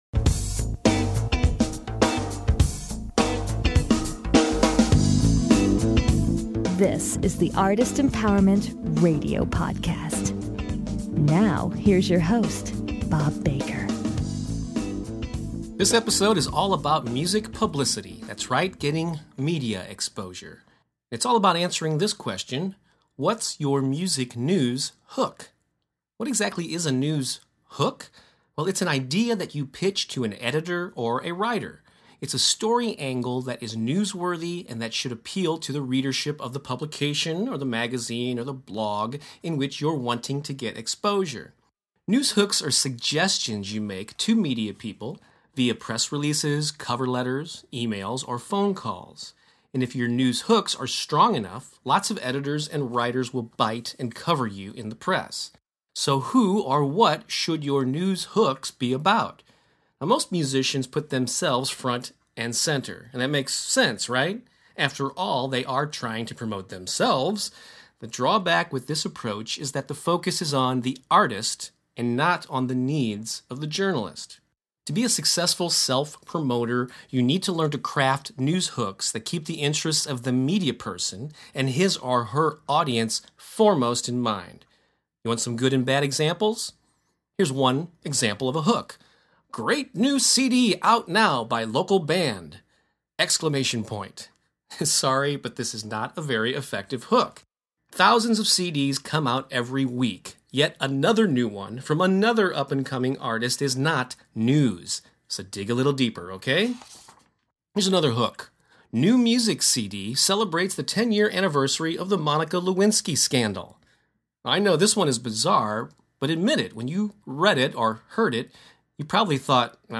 The show intro music is the beginning groove